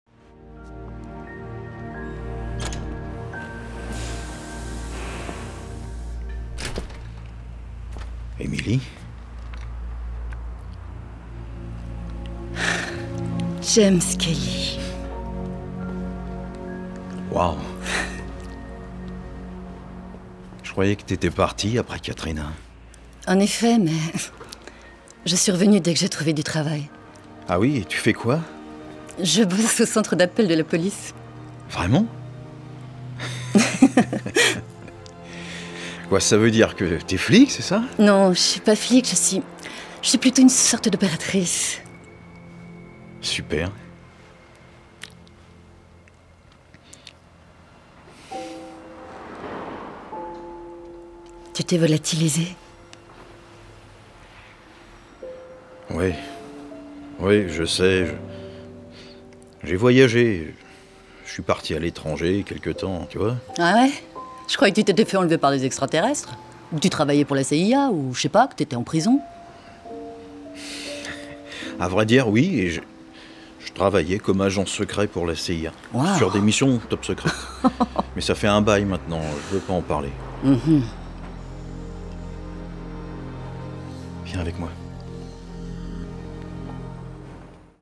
VOIX DOUBLAGE – FILM « Braquage à l’américaine » (James)